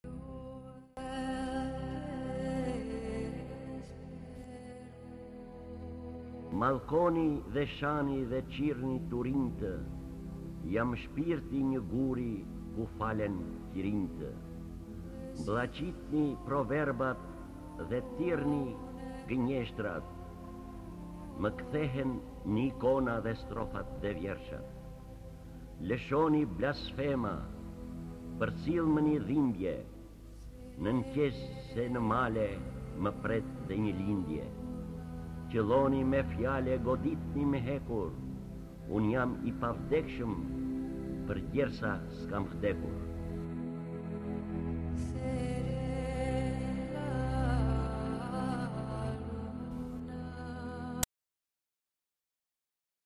D. AGOLLI - PROFETI I NËMUR Lexuar nga D. Agolli KTHEHU...